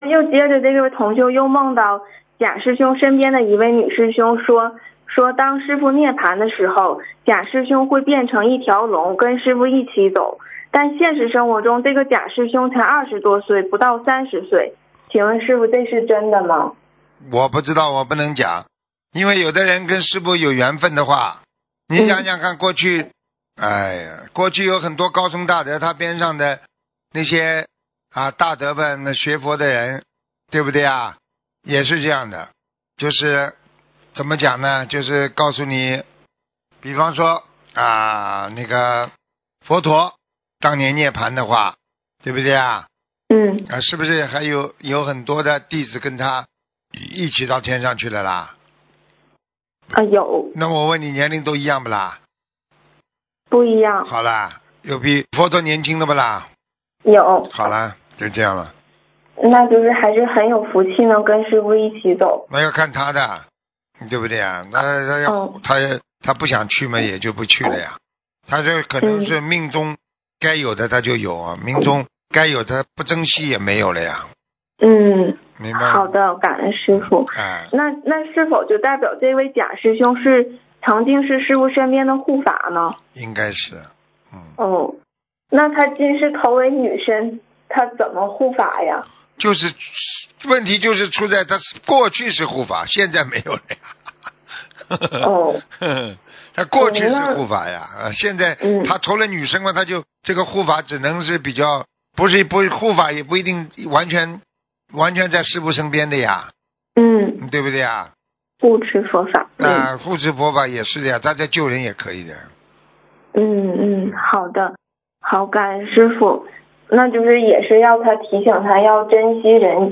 目录：☞ 2019年10月_剪辑电台节目录音_集锦